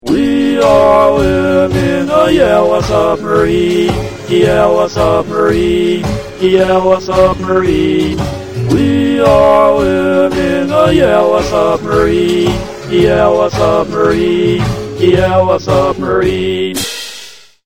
With drums, and harmony and all that.